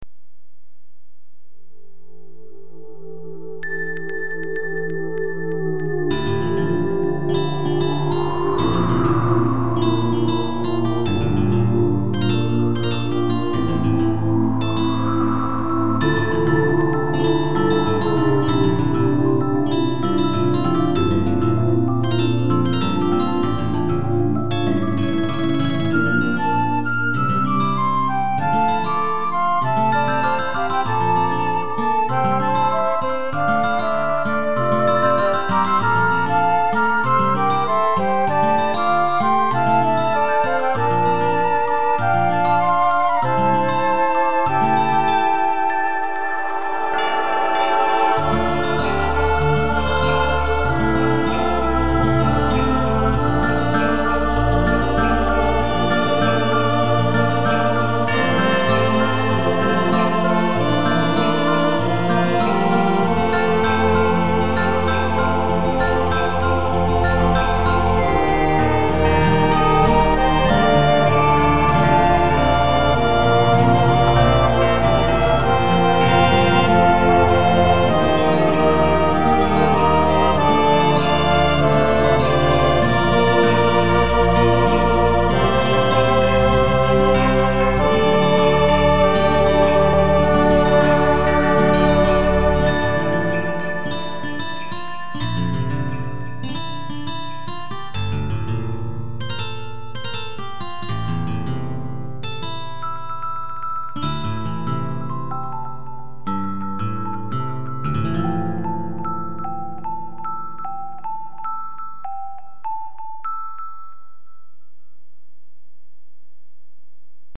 ただ 傍に近づきたいと ――願わくば 貴方の許に 静かなる聖夜を―― 解説 上のイメージ文ばかり暴走してますが、一言で表現するなら たぶん「憧れ」でしょうね。 曲としては、まっったくクリスマスらしくもない煮え切らない感じ。